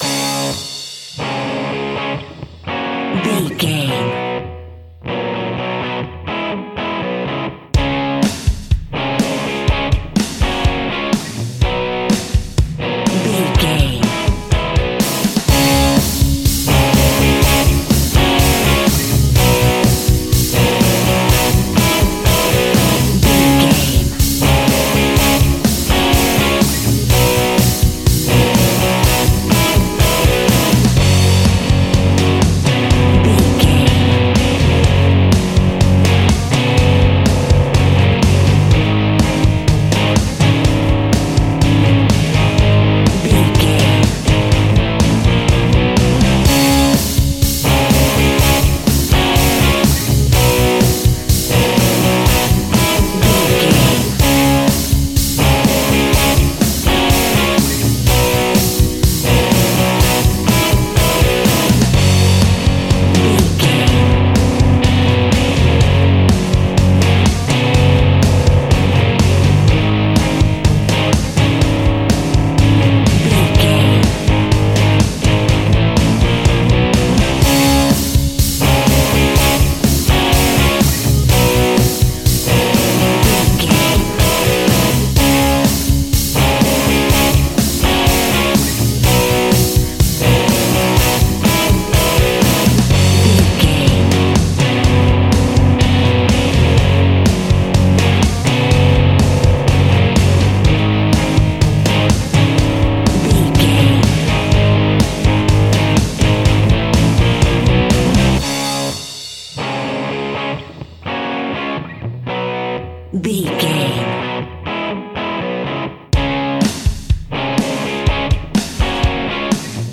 Uplifting
Ionian/Major
D♭
hard rock
blues rock
instrumentals
Rock Bass
heavy drums
distorted guitars
hammond organ